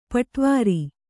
♪ paṭvāri